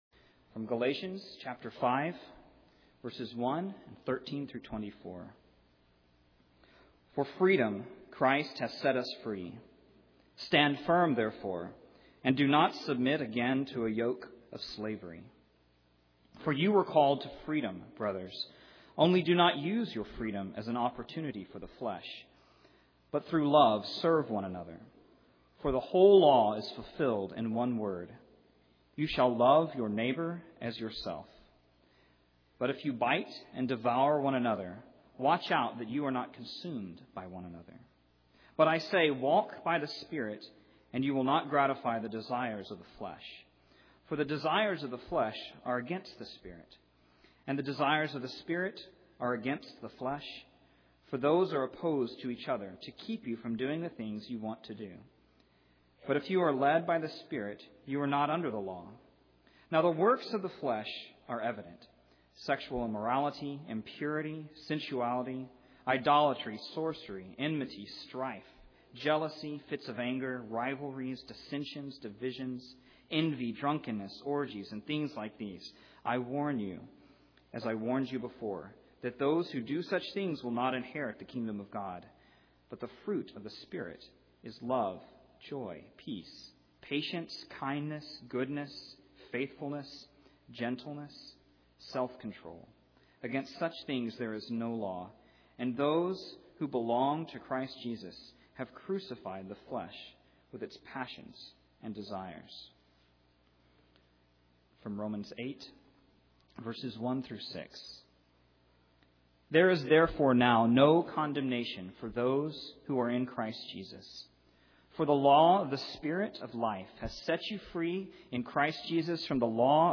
The Golden Chain Passage: Galatians 5:1, Galatians 5:13-24, Romans 8:1-6 Service Type: Sunday Morning